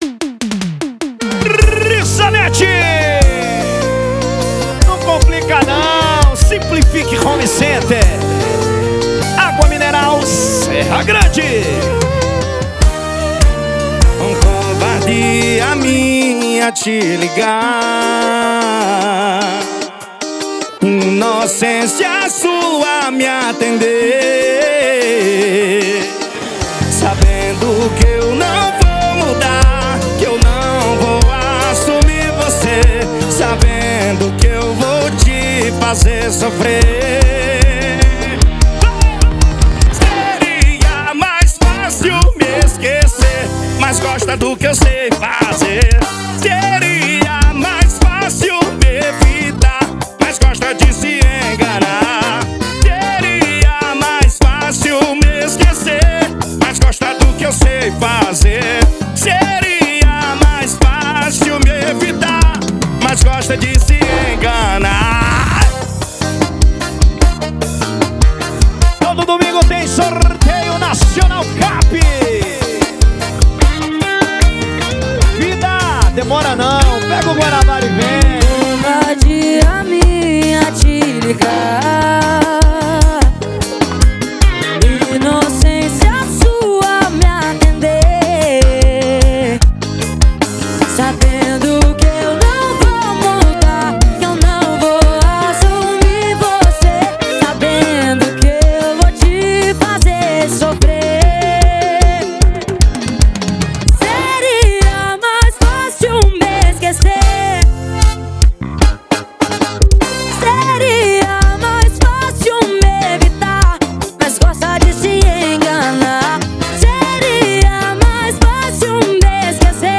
2024-02-14 22:40:16 Gênero: Forró Views